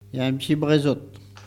Bouin
Locutions vernaculaires